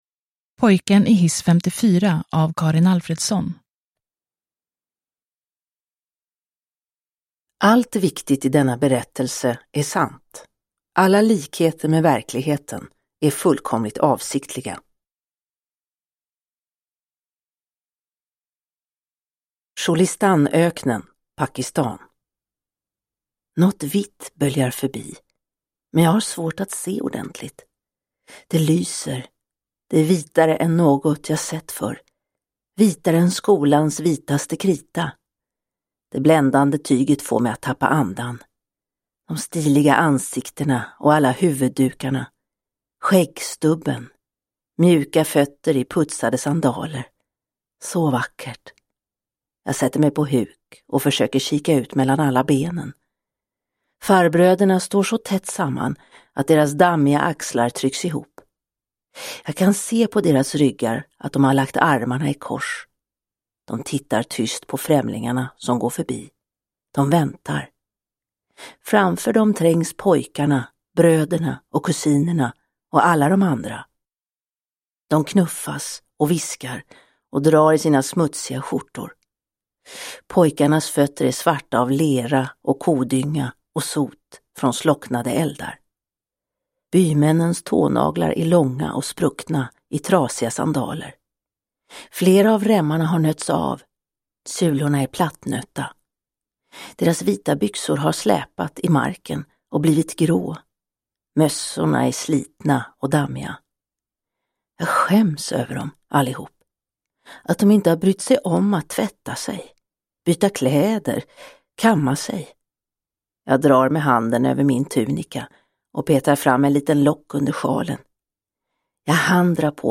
Pojken i hiss 54 – Ljudbok – Laddas ner